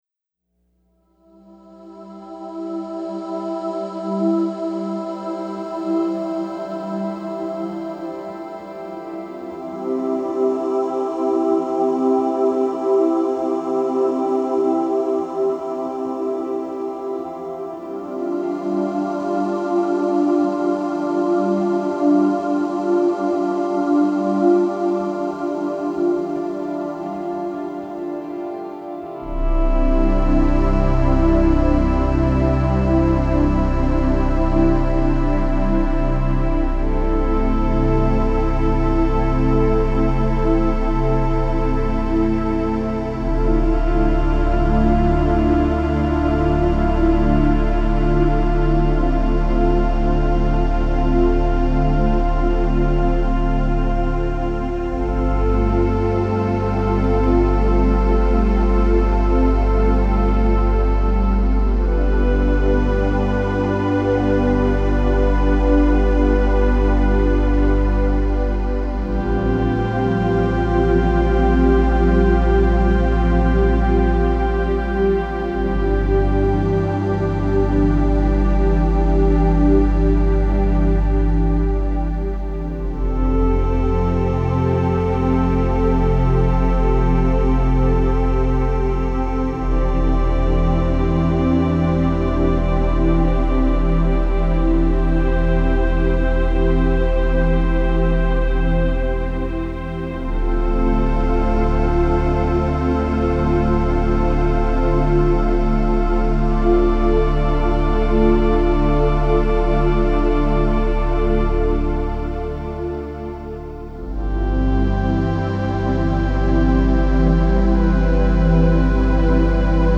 sedative therapeutic music
envelopes us in gentle continuous sound